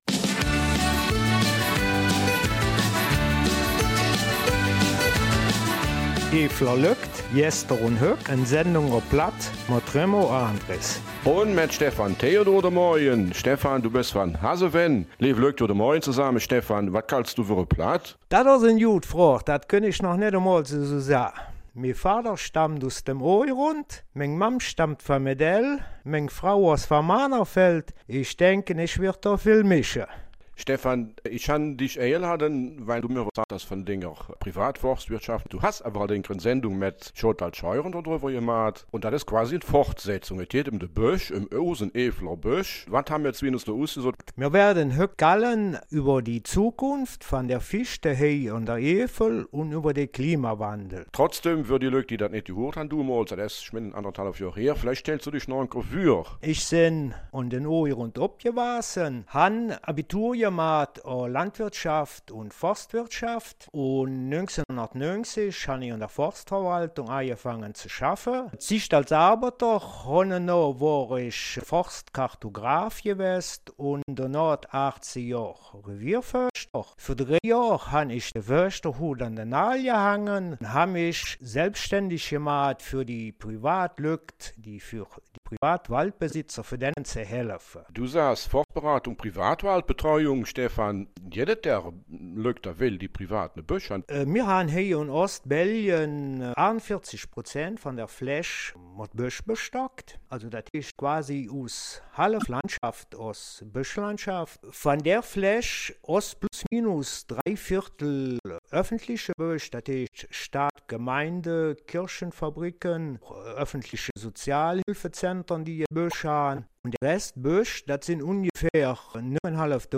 Eifeler Mundart: Die Zukunft der Fichte in der Eifel